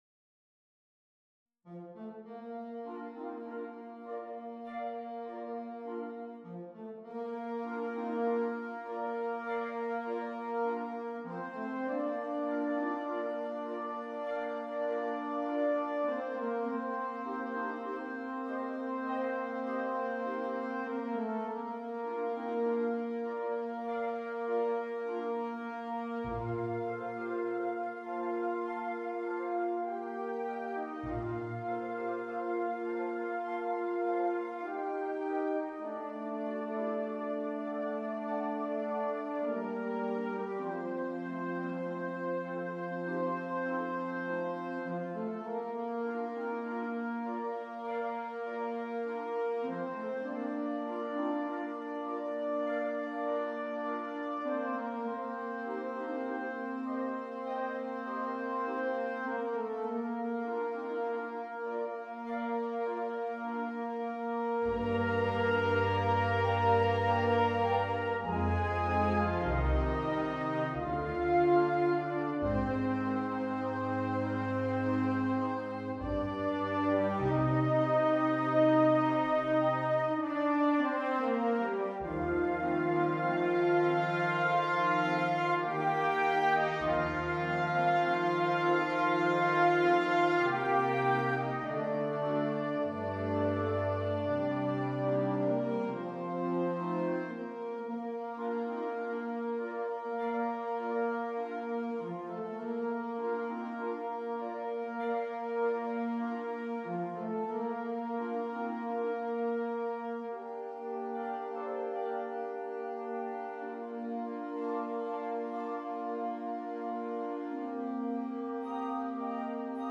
Gattung: Konzertwerk für Blasorchester
Besetzung: Blasorchester